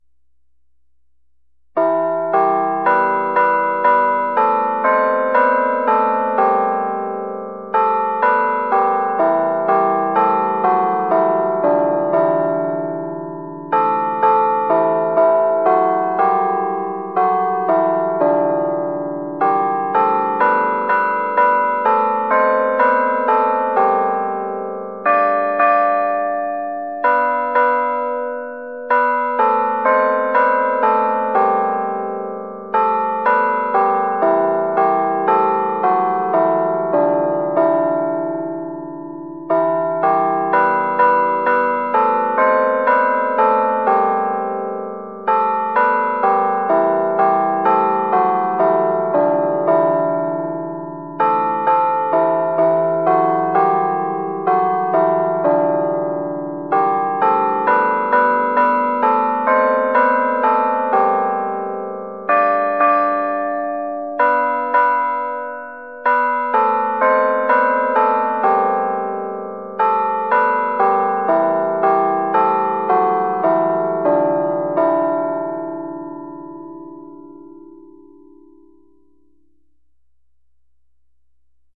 Our carillon controllers use real bell melodies.
• Realistic digital bell sounds that replicate traditional carillons